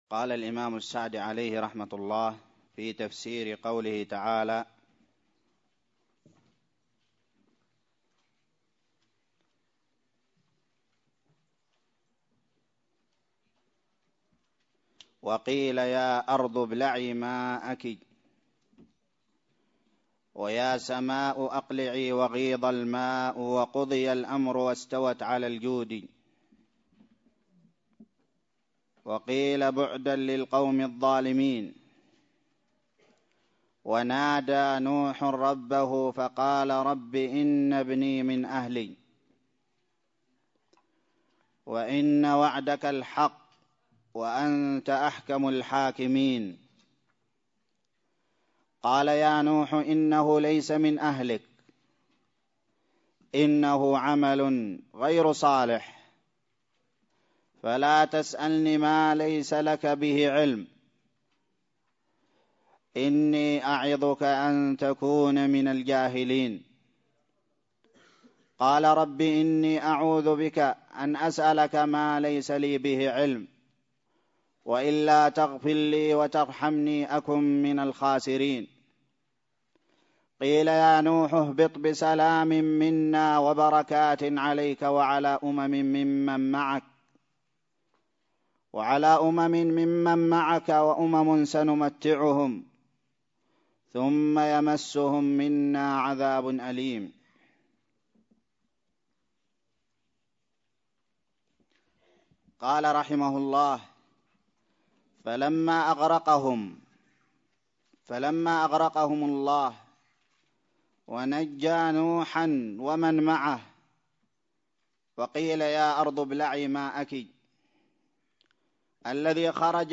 الدرس الخامس عشر من تفسير سورة هود
ألقيت بدار الحديث السلفية للعلوم الشرعية بالضالع